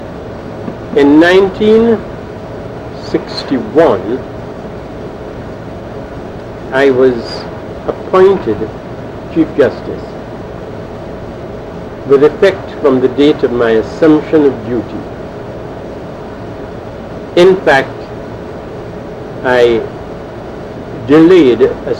Sir Ellis Clarke was President of the Republic of Trinidad and Tobago at the time when this interview was done.
l audio cassette